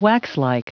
Prononciation du mot waxlike en anglais (fichier audio)
Prononciation du mot : waxlike